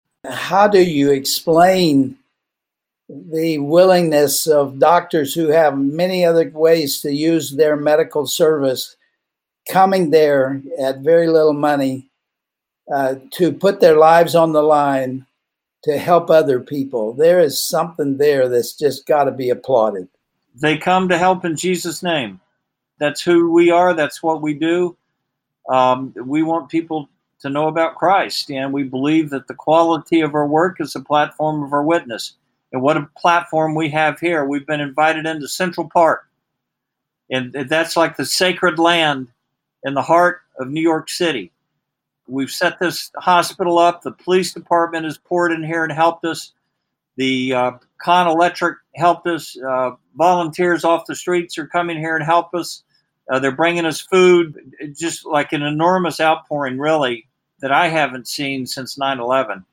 Family Talk radio broadcast